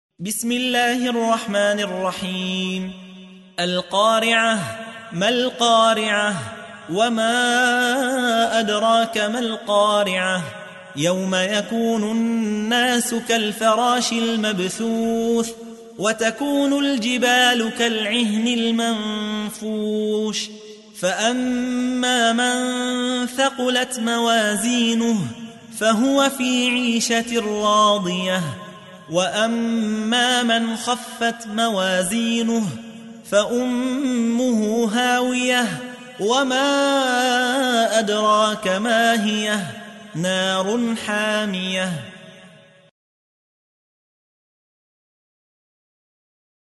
تحميل : 101. سورة القارعة / القارئ يحيى حوا / القرآن الكريم / موقع يا حسين